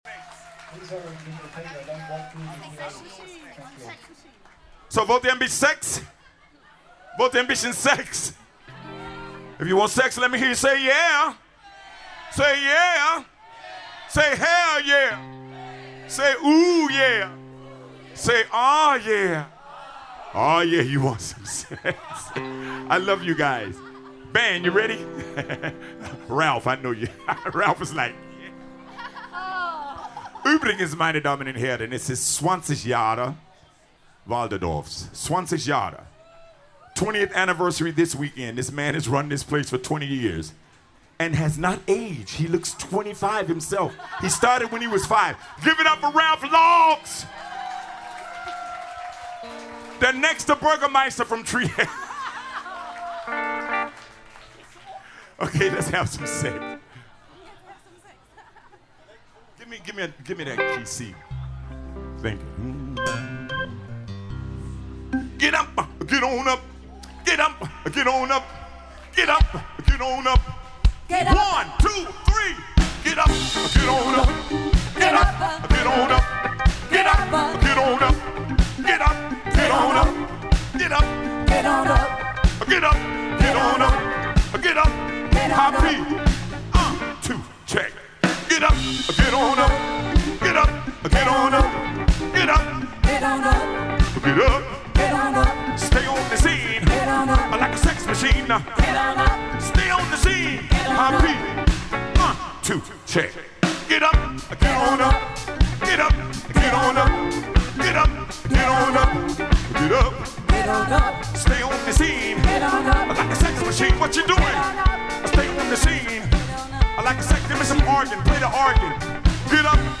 · Genre (Stil): Soul
· Kanal-Modus: stereo · Kommentar